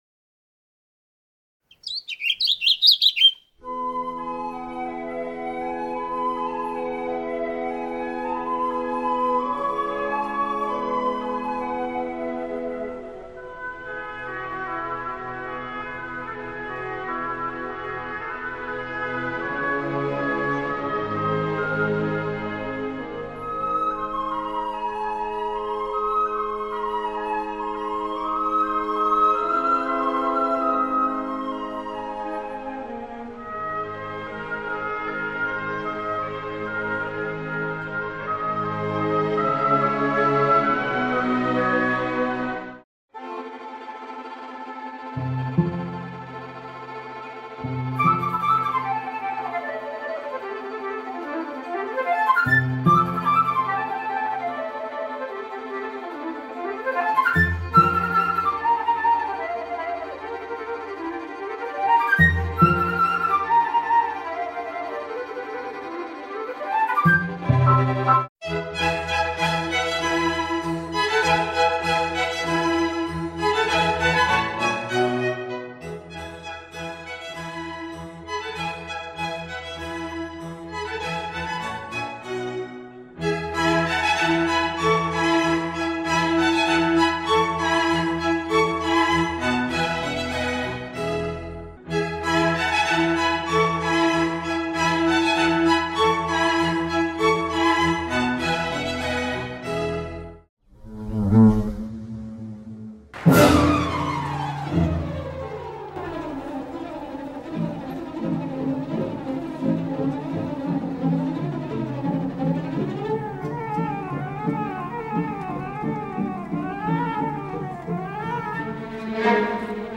La musique donne une impression de mouvement, de légèreté et de vie.
Le Printemps est un concerto pour violon et orchestre qui fait partie du cycle Les Quatre Saisons.
Flight of the Bumblebee est une musique très rapide, vive et tourbillonnante.
Elle donne l’impression d’entendre un bourdon qui vole dans tous les sens, en zigzaguant sans arrêt.
La musique est pleine d’énergie, de mouvement.
La musique avance peu à peu et crée une atmosphère à la fois calme, hypnotique et pleine d’énergie.